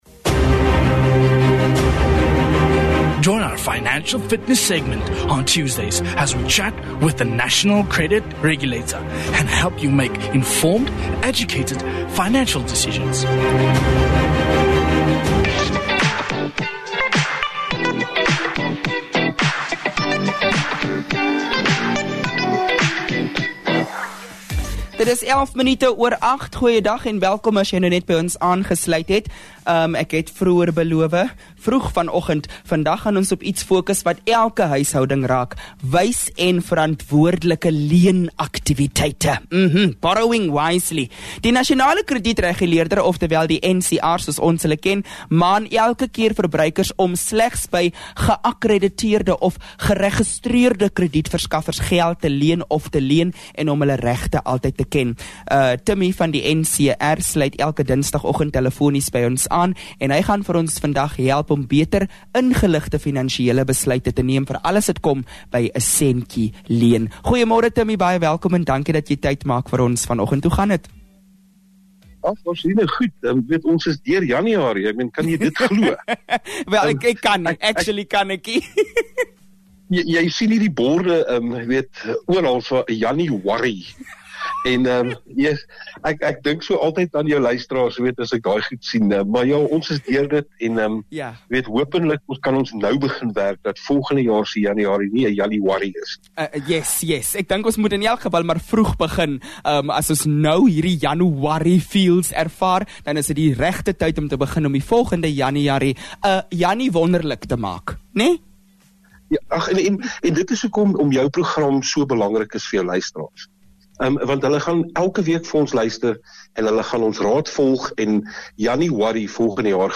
From personal loans and credit agreements to understanding interest rates, repayment terms, and hidden costs, this conversation sheds light on the factors many borrowers overlook.